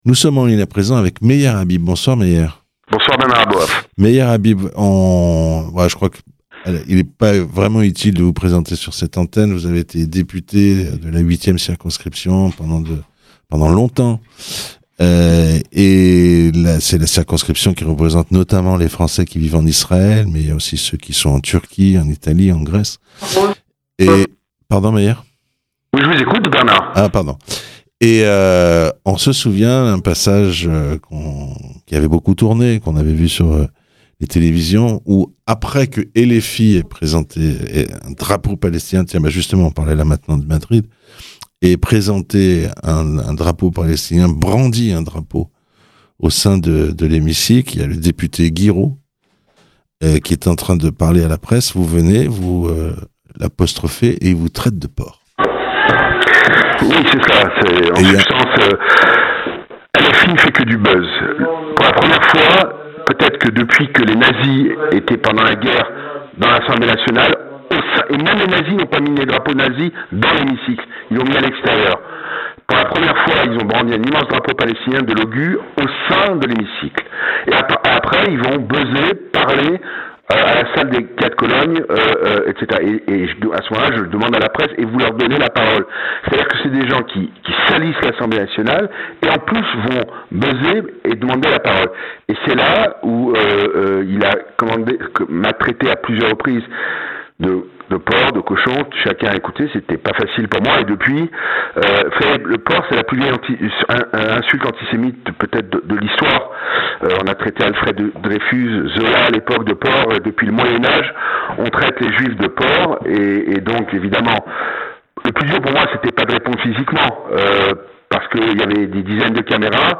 Meyer Habib invité de Radio Shalom la veille de son procès contre le député David Guiraud qui l'avait traité de "porc"